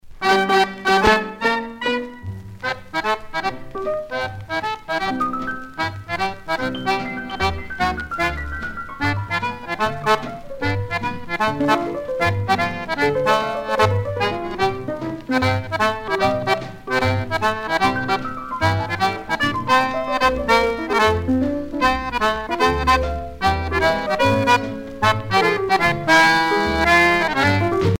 danse : fox-trot
Pièce musicale éditée